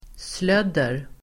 Uttal: [sl'öd:er]